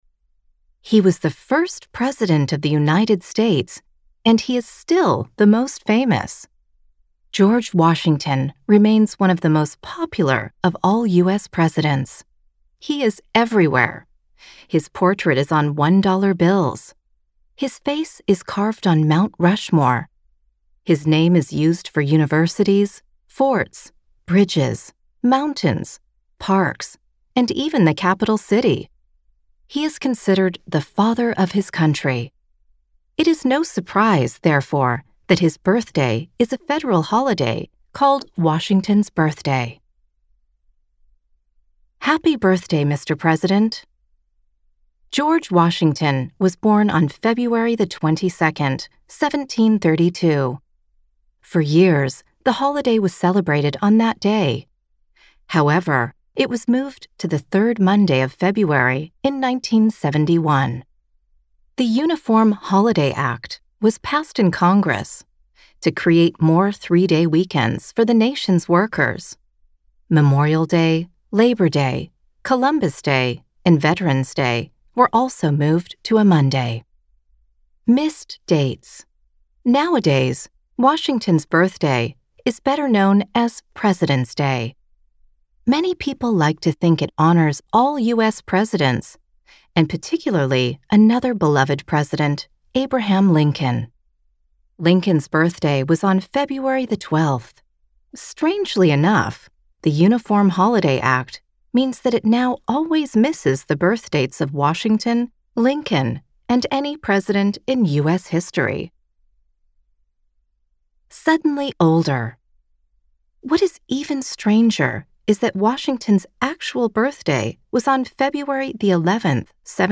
Speaker (American accent)